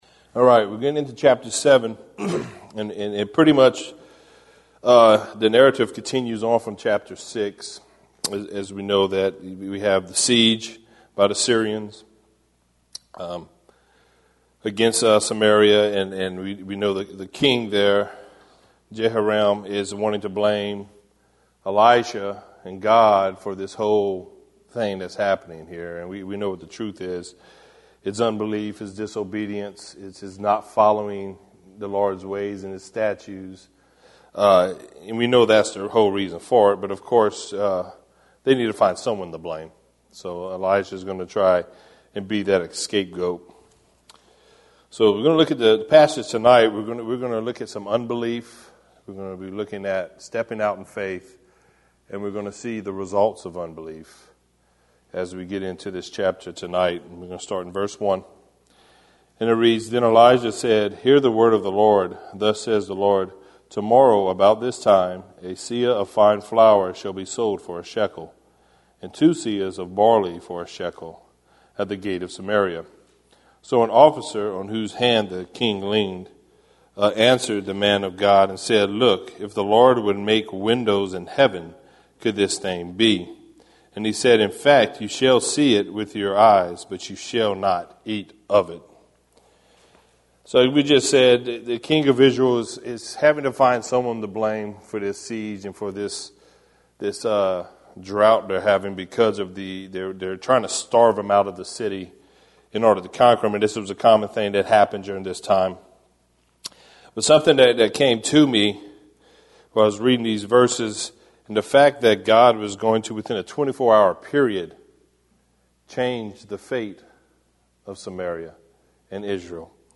verse by verse study